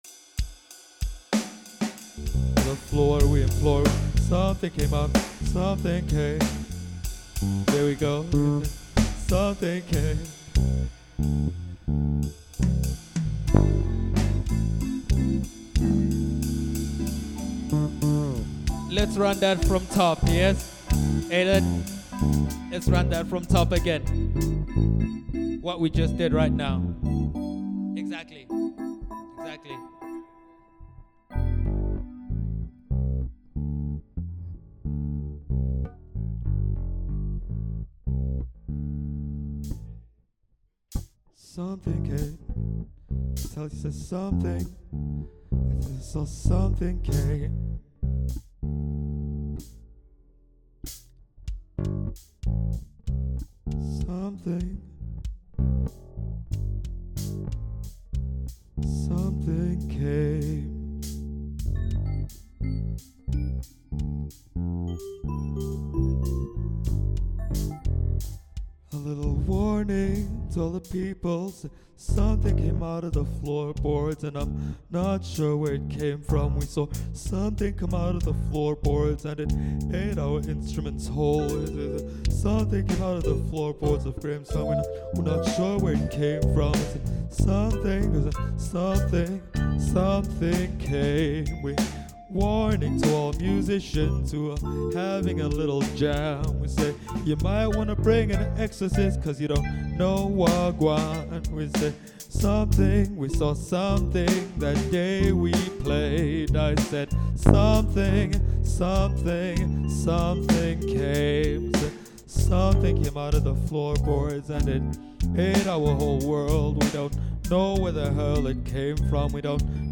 The band and I. Singing about that thing that came out the groundate ate our music.